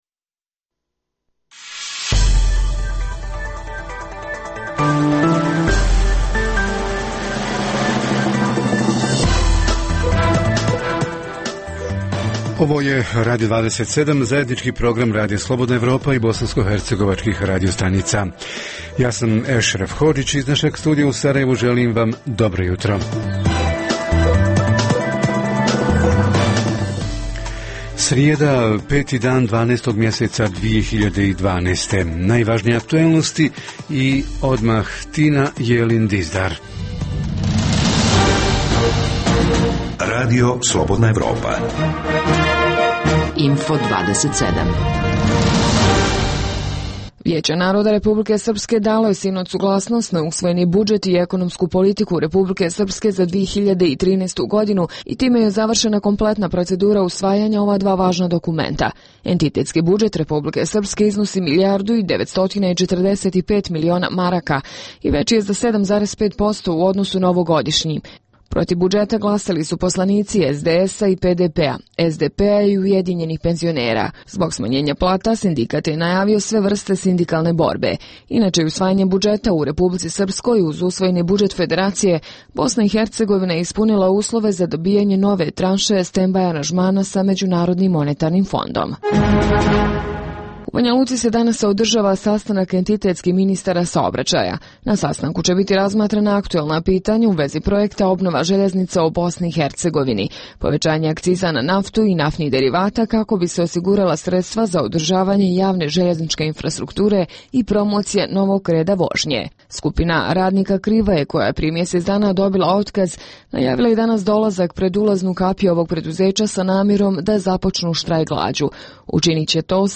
O tome će naši reporteri iz Olova, Višegrada, Zvornika i Tuzle.